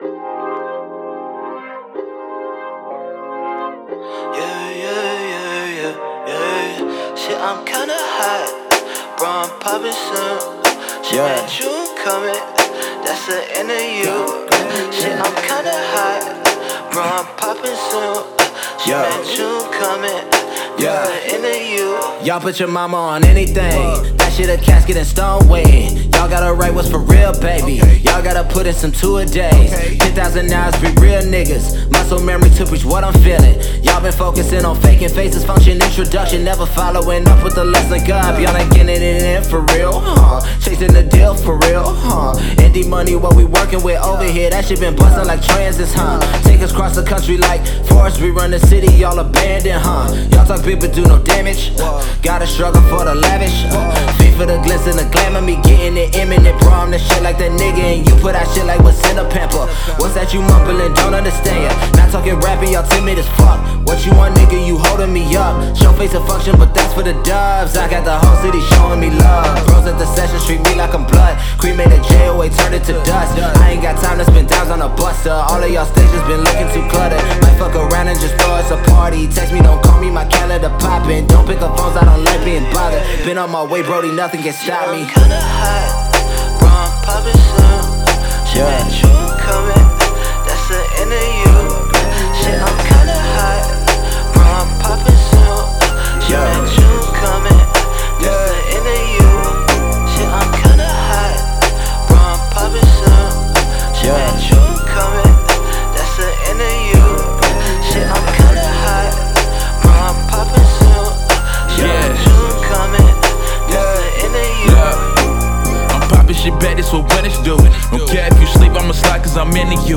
Indie Dallas Rapper
Genre-Blending
practically made for late night cruising under city lights
also features Dallas rapper
The song starts with lo-fi beats
laidback echoed chorus